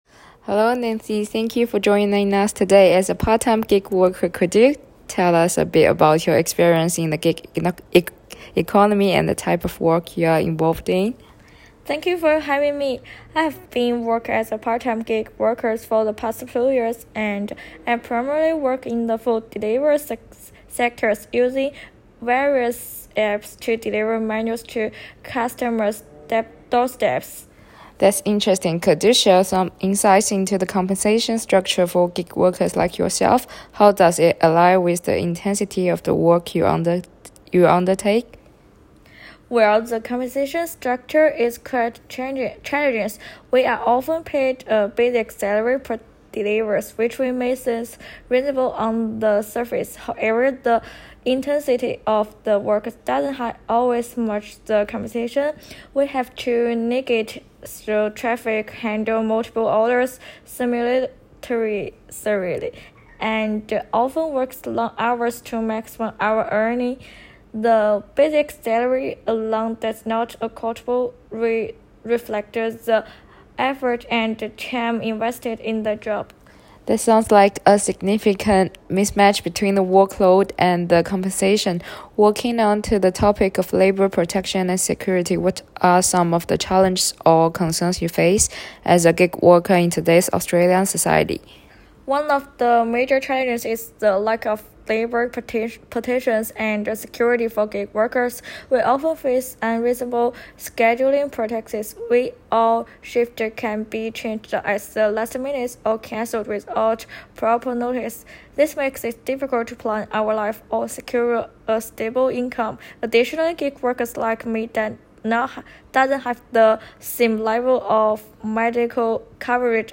interview.m4a